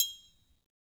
Triangle3-HitFM_v1_rr1_Sum.wav